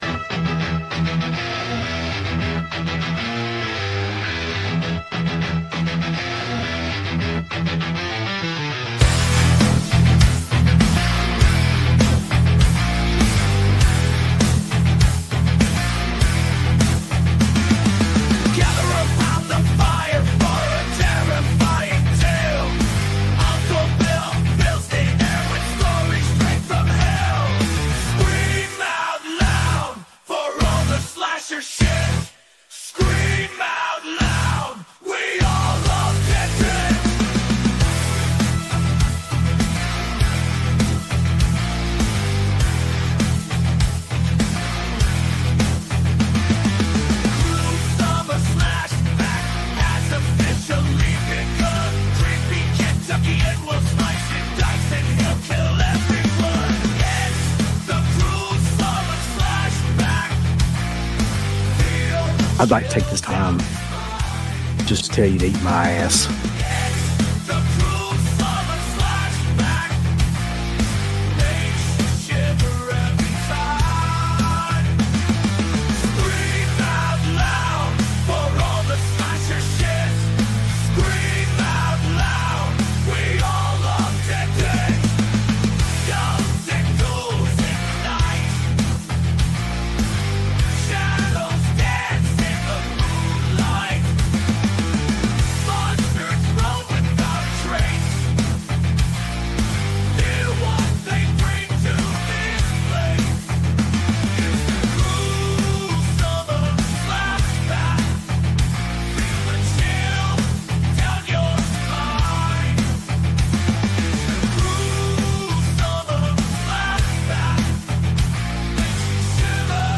The Original Horror Radio talk show, with interviews, reviews, and more.